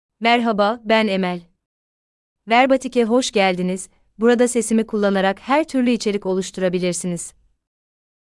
Emel — Female Turkish AI voice
Emel is a female AI voice for Turkish (Türkiye).
Voice sample
Listen to Emel's female Turkish voice.
Emel delivers clear pronunciation with authentic Türkiye Turkish intonation, making your content sound professionally produced.